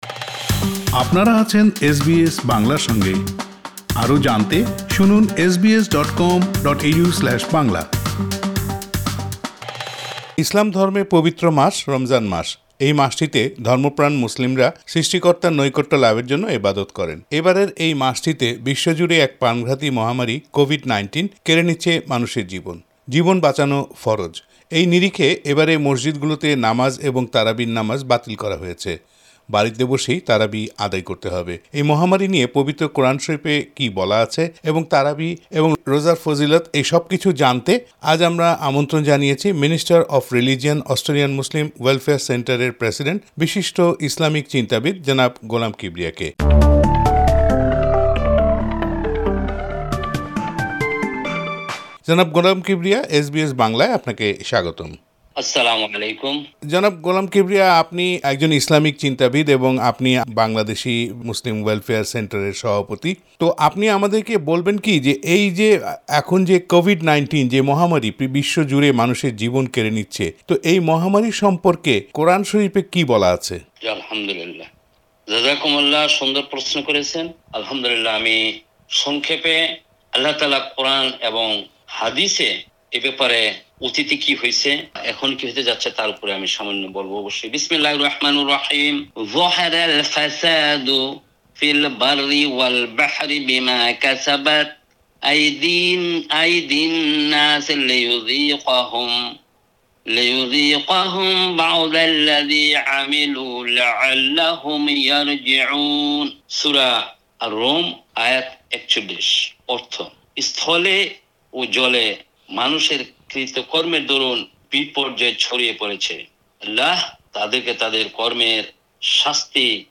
এসব কিছু নিয়ে এস বি এস বাংলার সঙ্গে কথা বলেছেন মিনিস্টার অফ রিলিজিওন।